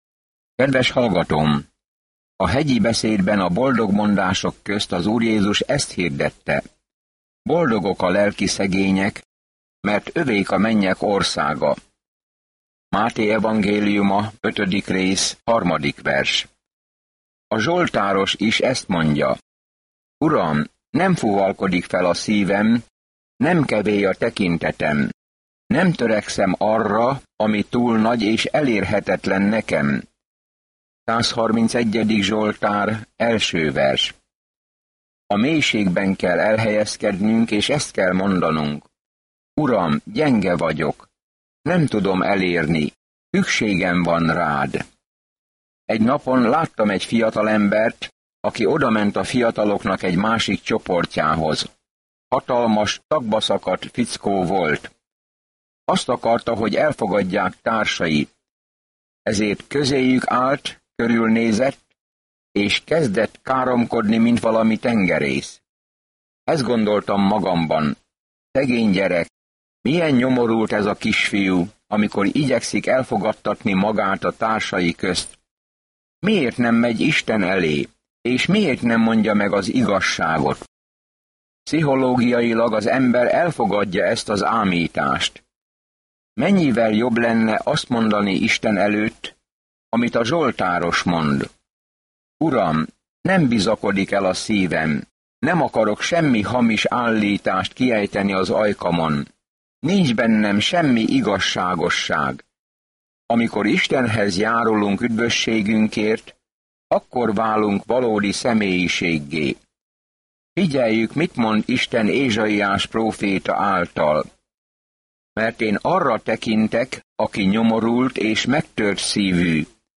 There is an audio attachment for this devotional.